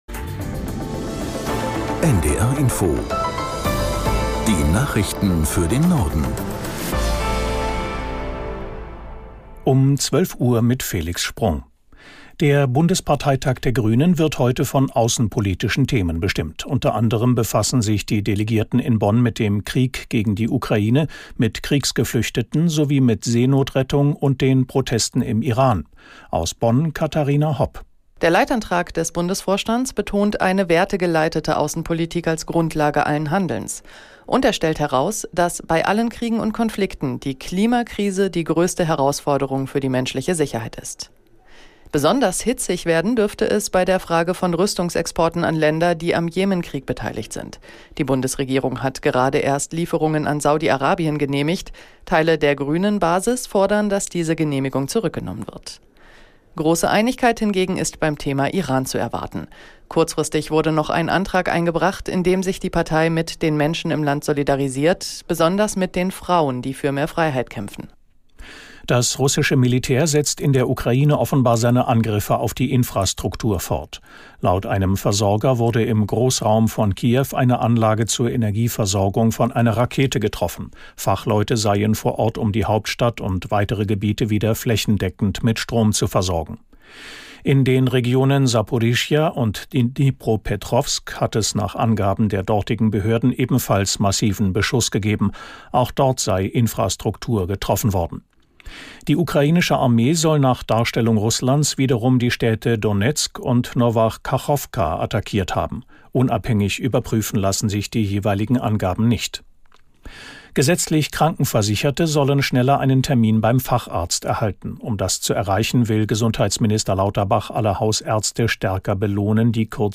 Nachrichten - 15.10.2022